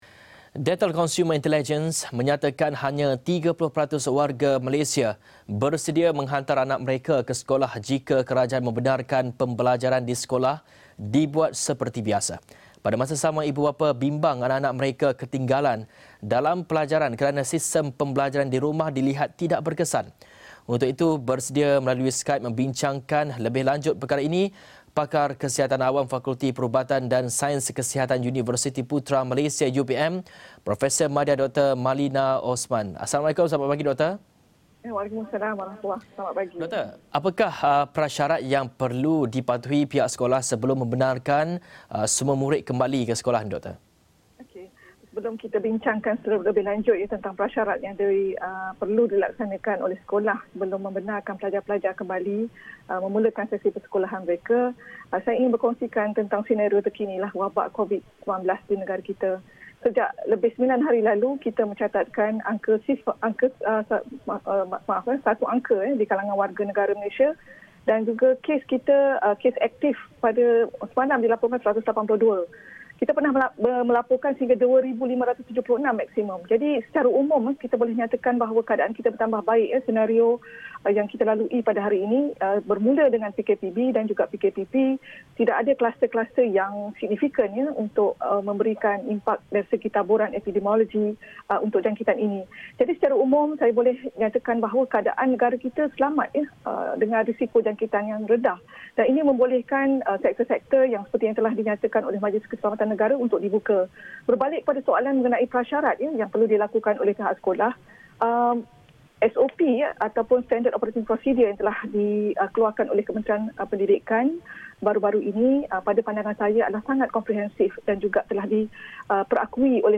bersedia melalui Skype membincangkan lebih lanjut perkara ini